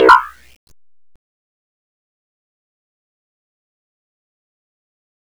Ion gun shoot.wav
ion_gun_shoot.wav